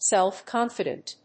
/ˌsɛˈlfkɑnfʌdʌnt(米国英語), ˌseˈlfkɑ:nfʌdʌnt(英国英語)/
アクセントsélf‐cónfident